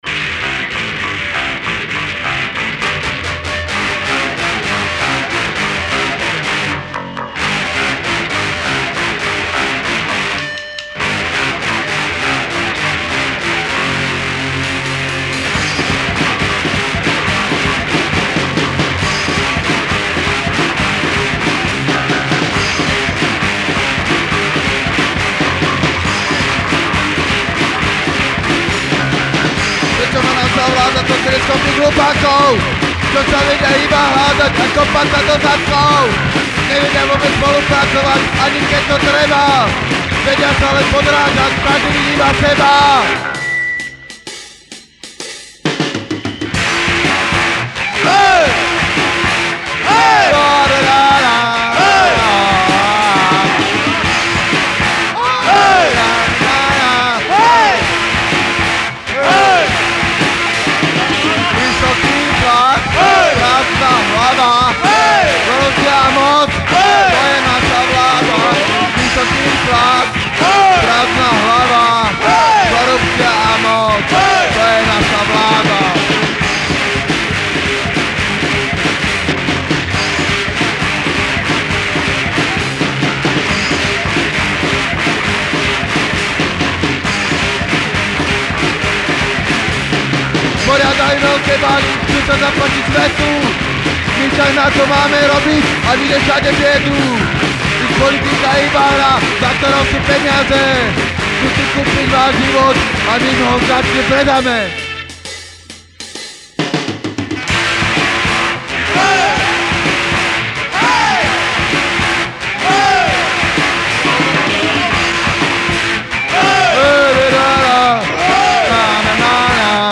Hardcore z Považia